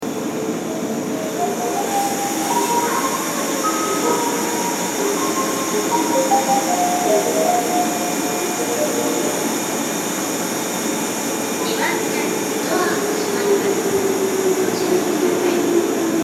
西府駅　Nishifu Station ◆スピーカー：CLD標準型
2番線発車メロディー